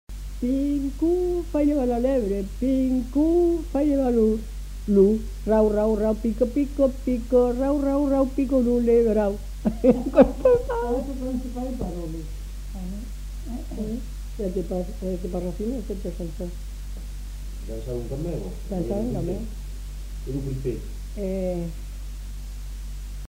Lieu : Cancon
Genre : chant
Effectif : 1
Type de voix : voix de femme
Production du son : chanté
Danse : polka piquée